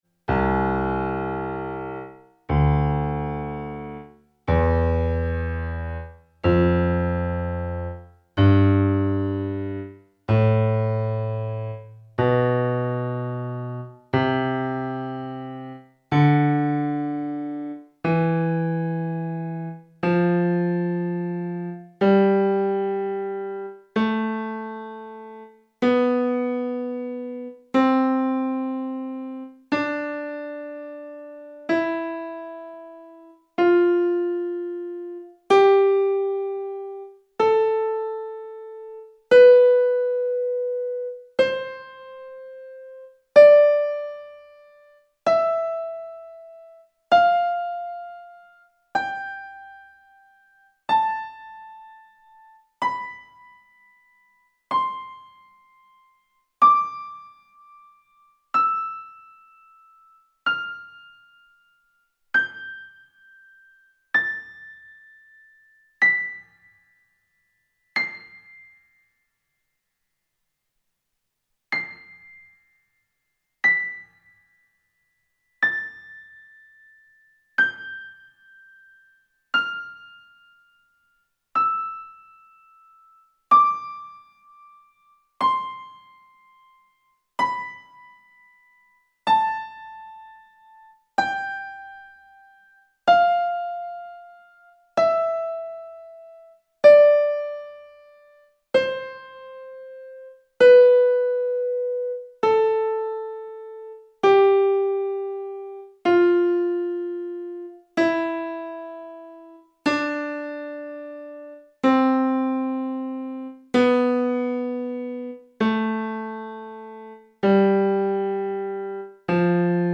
Tonleitern zum Mitsingen
aufsteigende und absteigende große Sekunden
tonleitern-sekunden.mp3